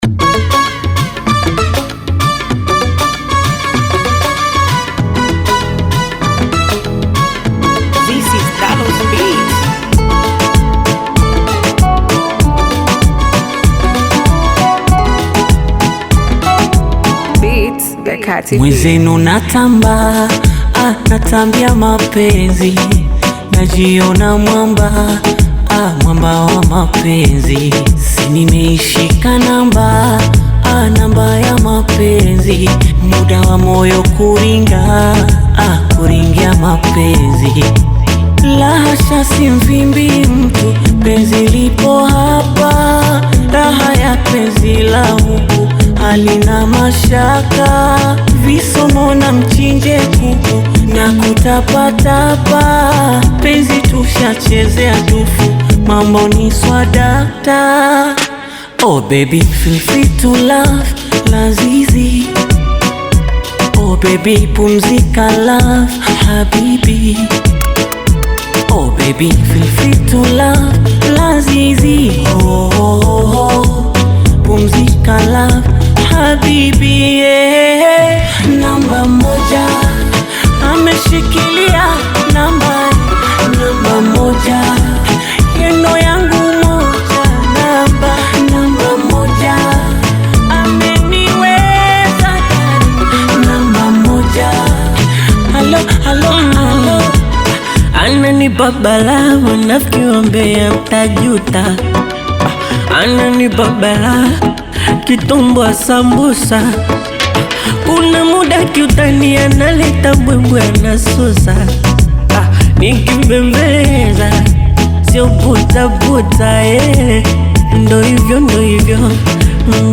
Afrobeat
With its upbeat tempo and catchy sounds